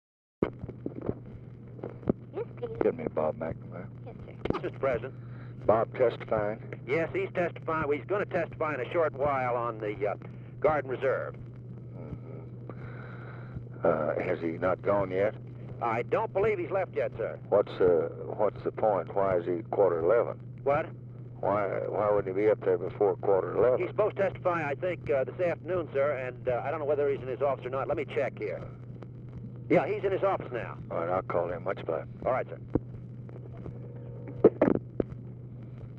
Conversation with CYRUS VANCE, March 1, 1965
Secret White House Tapes